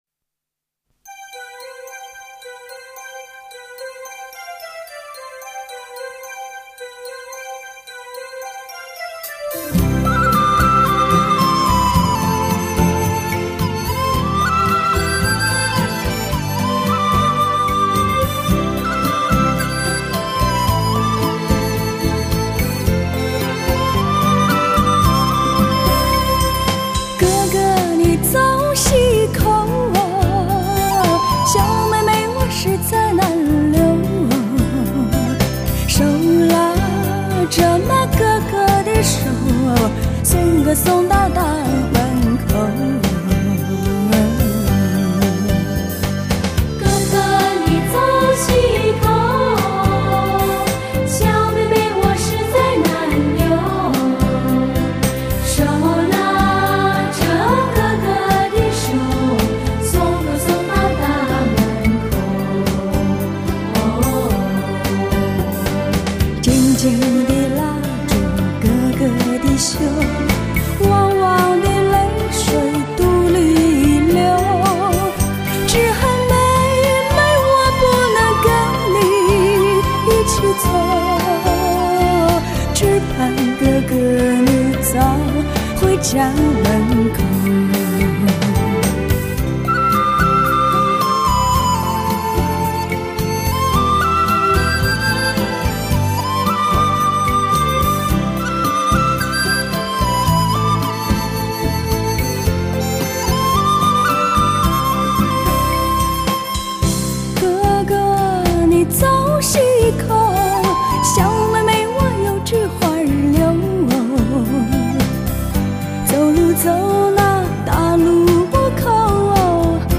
充满磁性的女声演绎天籁般的合唱，乡村民谣节奏元素，爵士布鲁斯特色，拉丁华尔兹风韵独特的创新风格